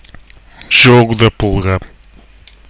pronunciation courtesy